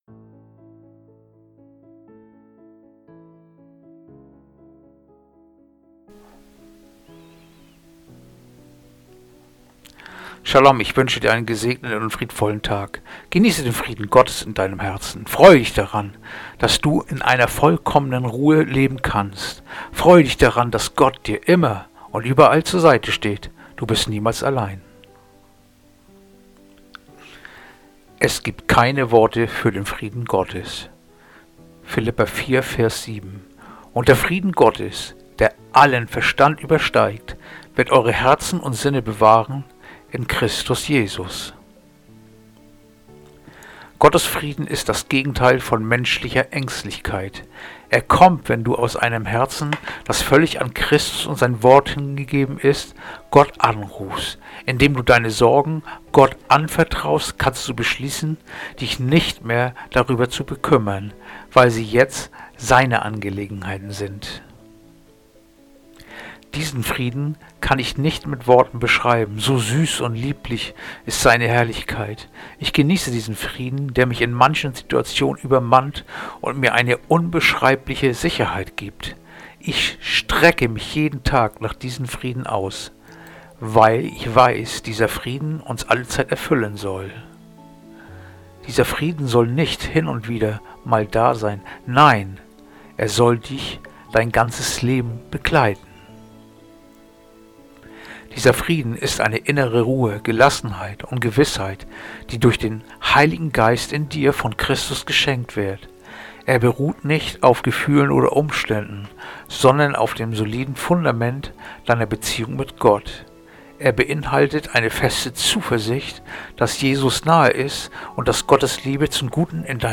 Andacht-vom-01-Februar-Philipper-4-7
Andacht-vom-01-Februar-Philipper-4-7.mp3